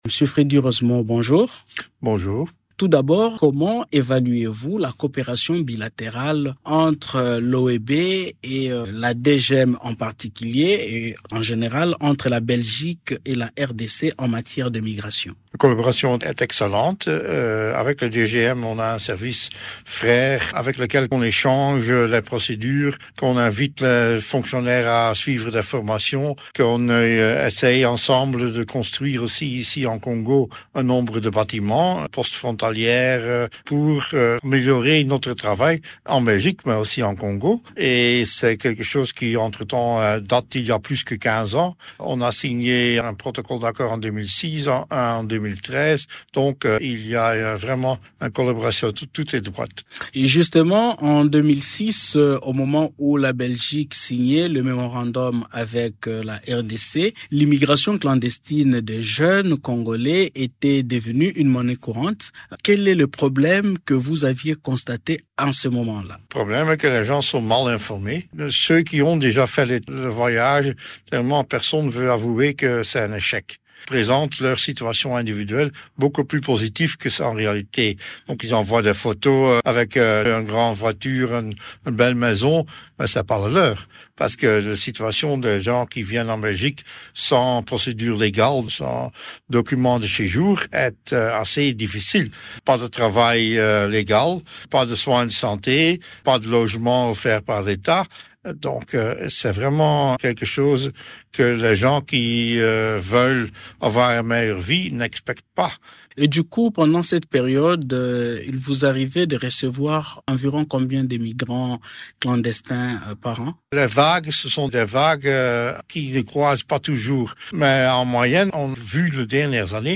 Invité de Radio Okapi, le Directeur général de l’Office belge des étranger (OEB), en séjour à Kinshasa, Freddy Roosemont affirme avoir enregistré en 2023, plus de 1 200 demandes de protection et plus de 650 depuis le début de cette année.